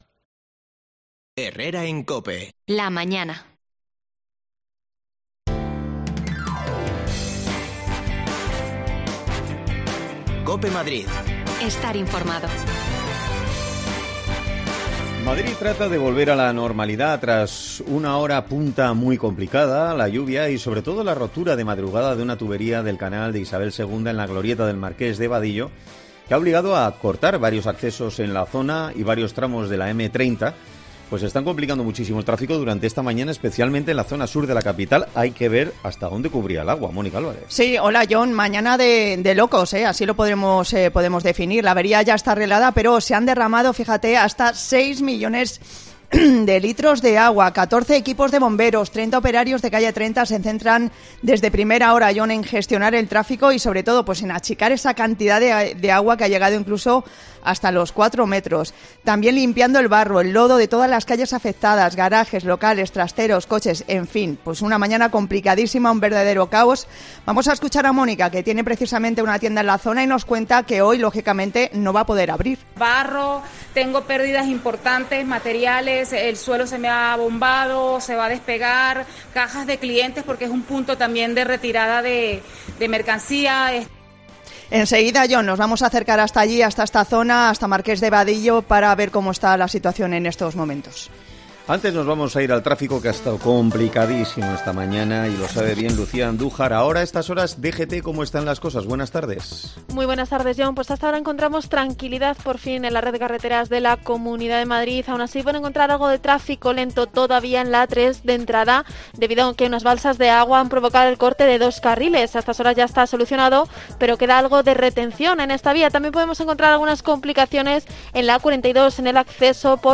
AUDIO: Una rotura en una tuberia del Canal de Isabel II en Marques de Vadillos provoca el caos en esta mañana de jueves. Te lo contamos desde alli
Las desconexiones locales de Madrid son espacios de 10 minutos de duración que se emiten en COPE , de lunes a viernes.